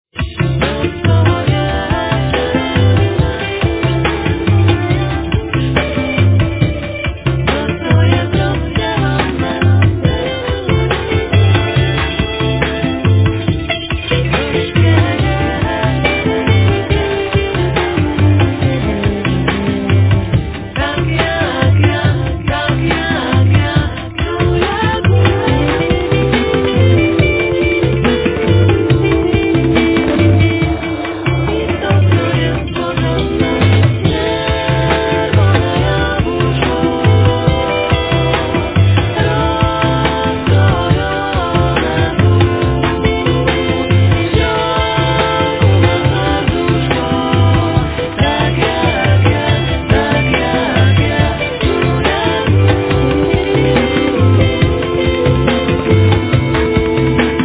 Cello, Vocals
Cello, Vocals, Zither, Drums [Hang]
Acoustic Bass
Bass Clarinet
Drums
Electric Piano
Trumpet
とても美しいジャズ・ミュージックとして楽しめる音楽です。